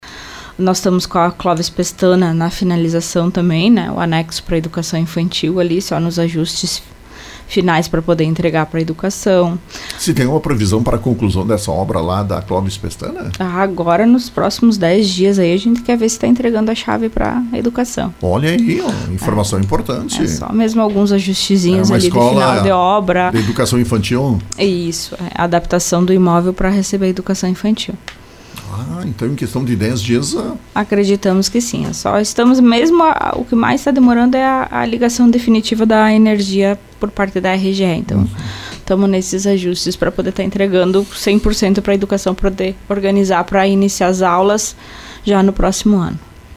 Foi o que transmitiu a secretária municipal do Planejamento de Lagoa Vermelha, Fabiana Prestes, na manhã desta segunda-feira, no programa Bom Dia Cidade. A obra está em fase de conclusão.